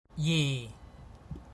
ji4ji/4